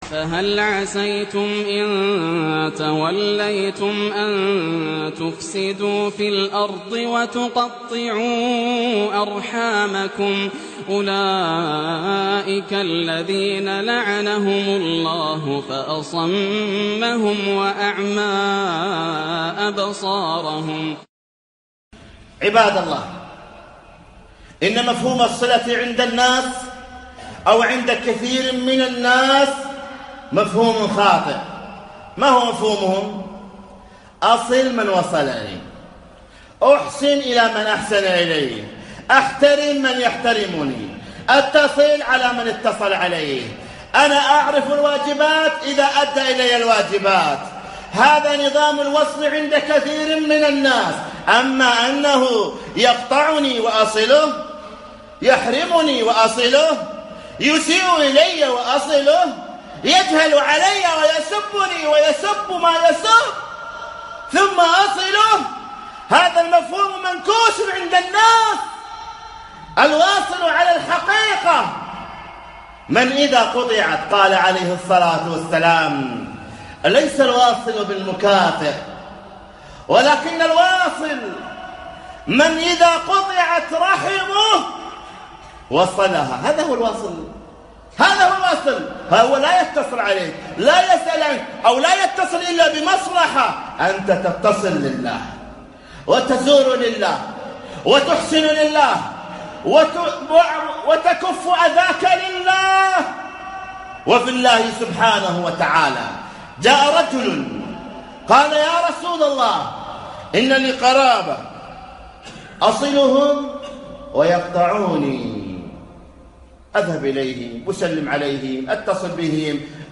مقتطف من خطبة بعنوان حقوق الأرحام